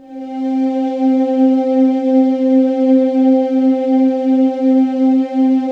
Index of /90_sSampleCDs/USB Soundscan vol.28 - Choir Acoustic & Synth [AKAI] 1CD/Partition D/11-VOICING